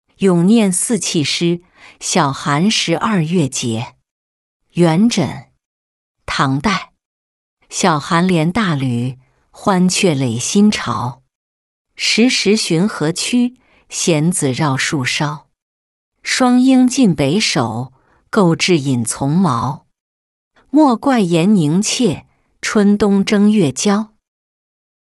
咏廿四气诗·小寒十二月节-音频朗读